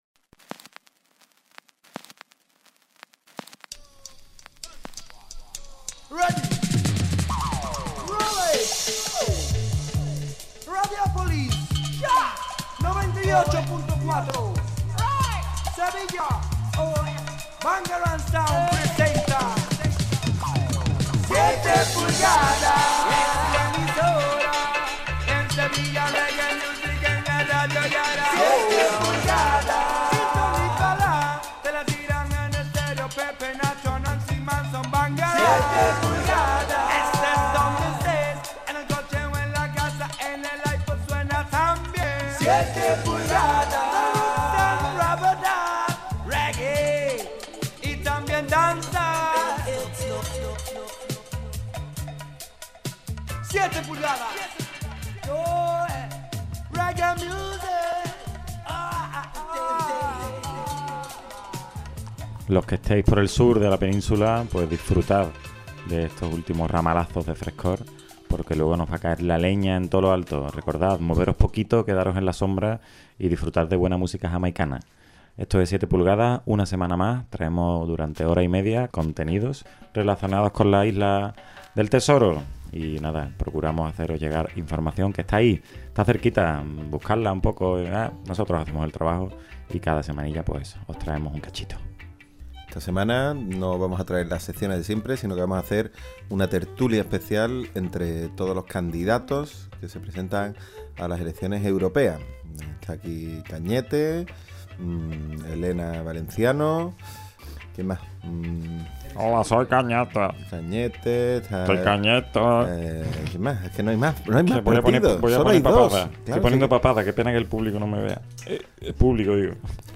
Presentado y dirigido por la Bangarang Sound y grabado en la Skuderbwoy House.